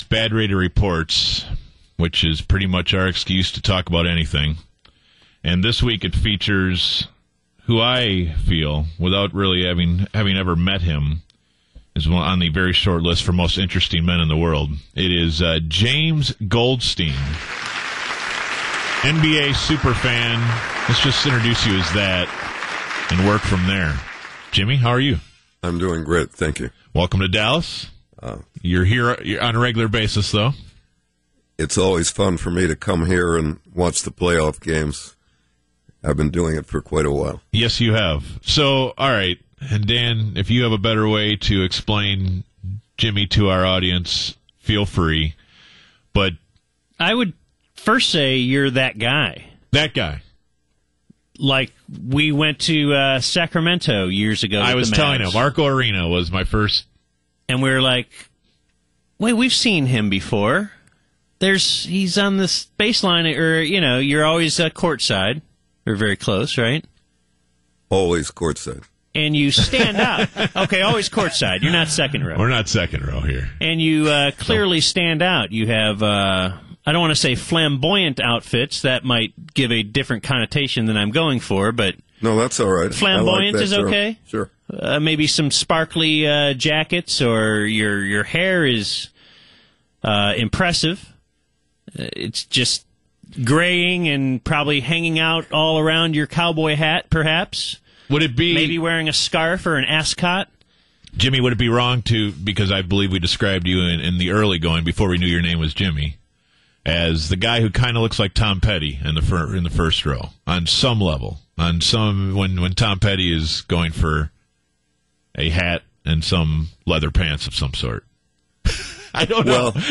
The most interesting man in the NBA world pays a visit to the BaD Radio boys.